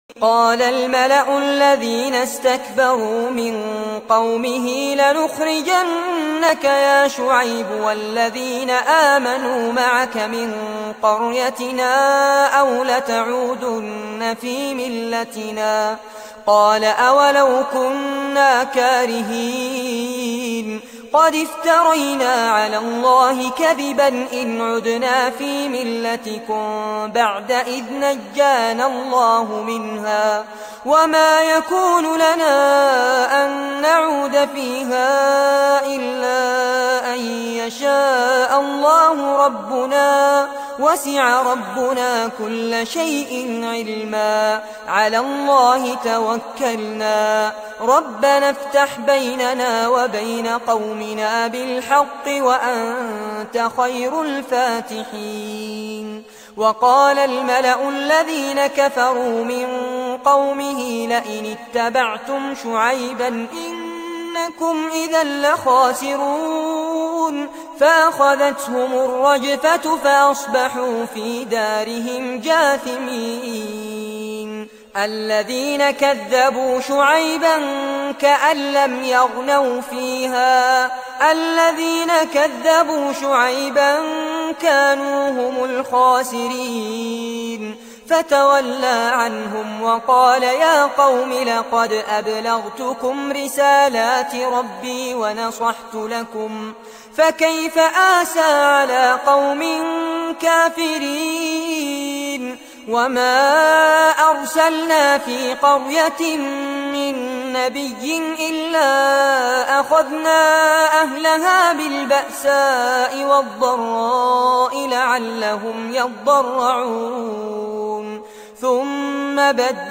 فارس عباد - ترتیل جزء نهم قرآن
Fares-Abbad-Quran-Juz-09.mp3